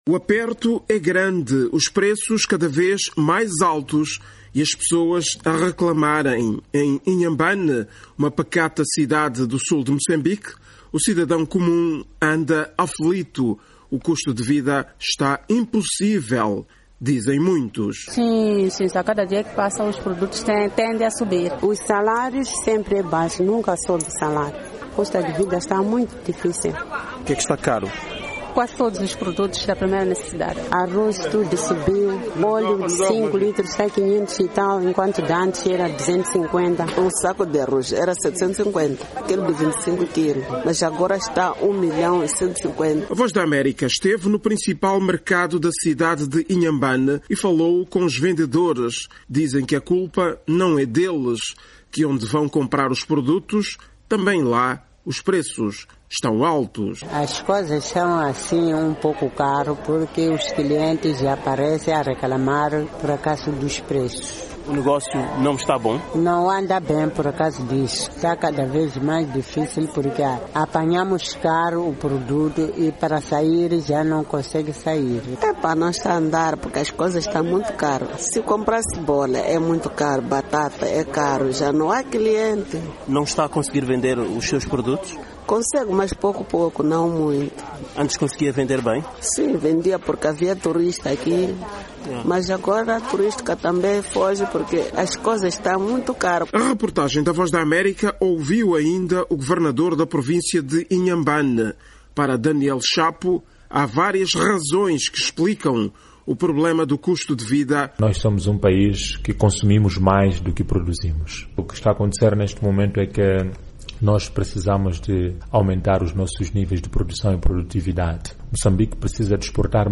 A VOA esteve no principal mercado da cidade de Inhambane e falou com os vendedores.
A reportagem da VOA ouviu ainda o Governador de Inhambane.